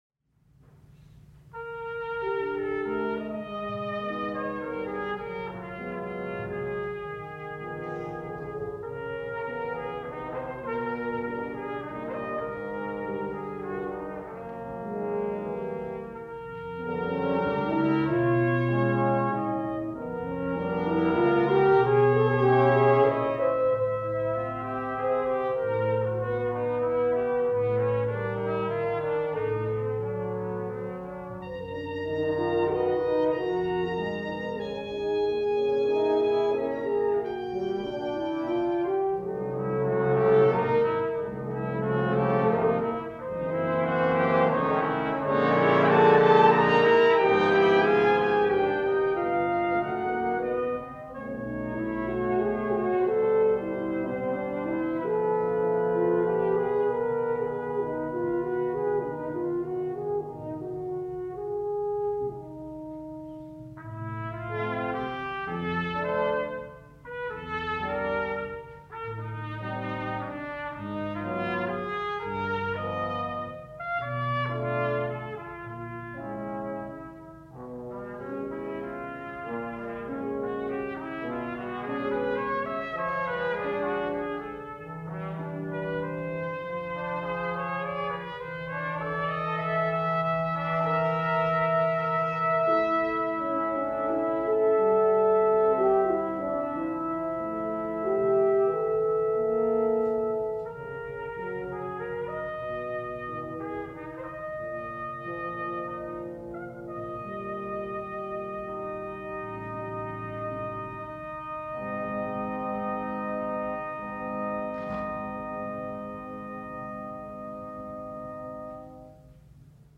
Concert Performance October 7, 1973
Audience nearly filled the house.
using a half-track, 10” reel-to-reel Ampex tape recorder
Armstrong Auditorium, Sunday at 4:00 PM
Brass Suite
Valse